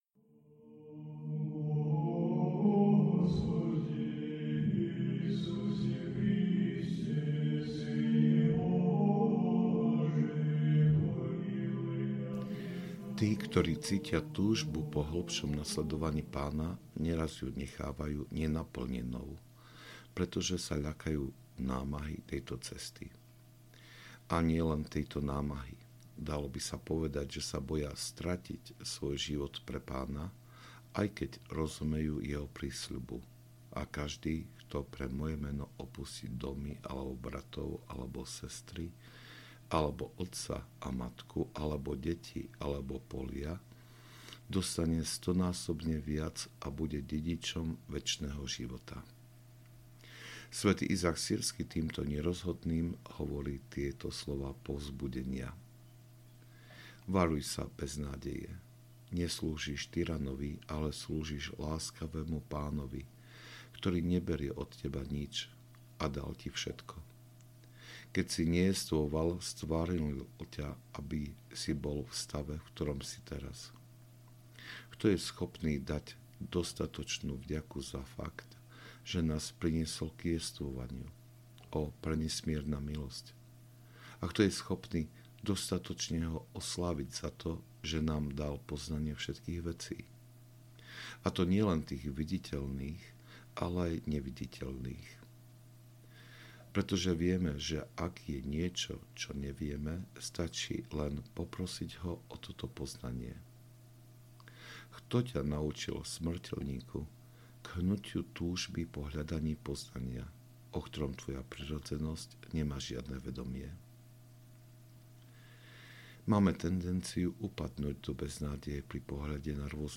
Nad slovami sv. Izáka Sýrskeho - Homília 25 – 45 audiokniha
Ukázka z knihy